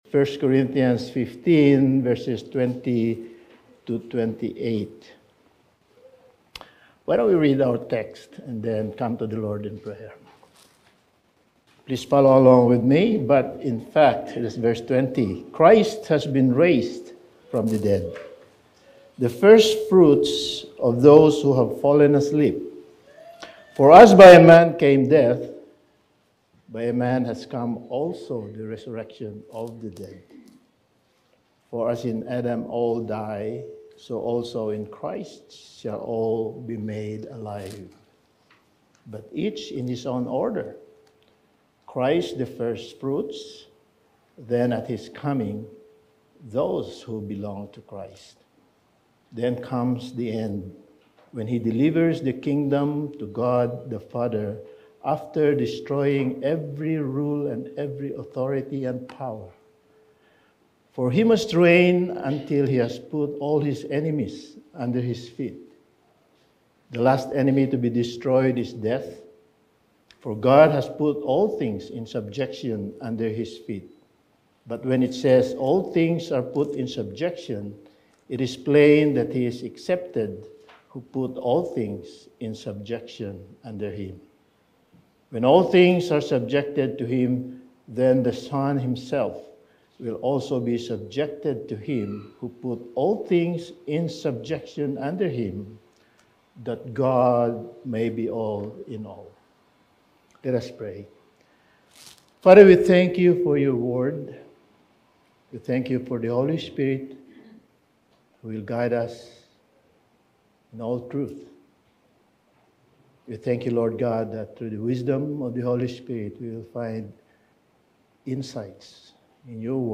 Topical Sermon Passage: 1 Corinthians 15:20-28 Service Type: Sunday Morning